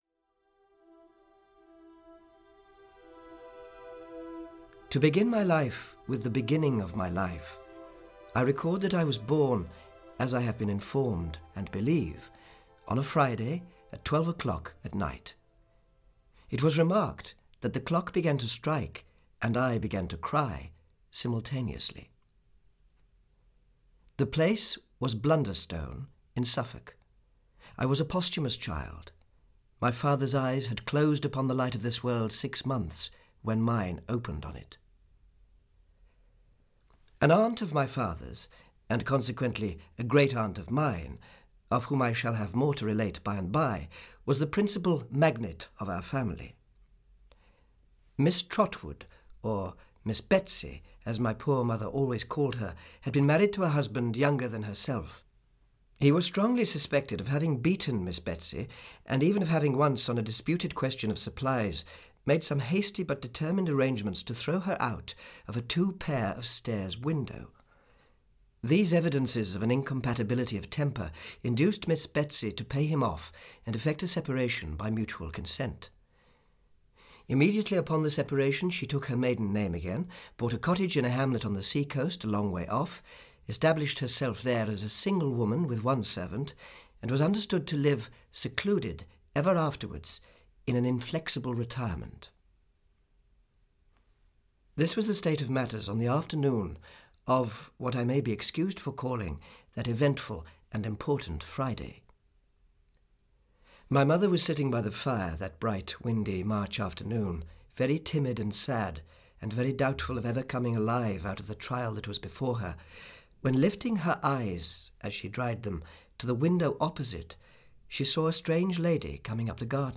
Audio kniha
• InterpretAnton Lesser